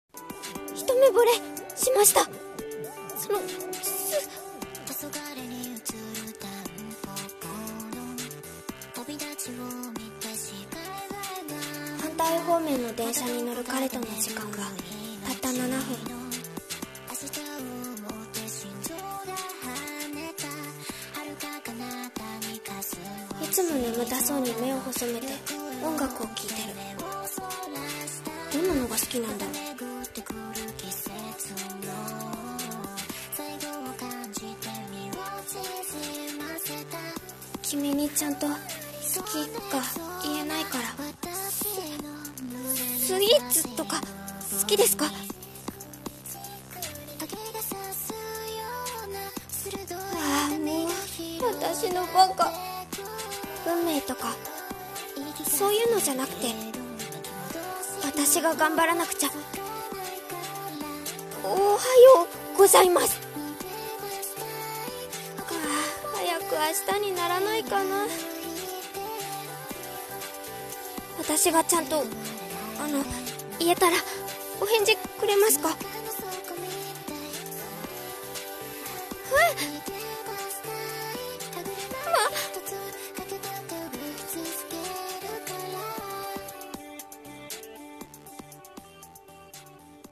【二人声劇】初恋オランジェット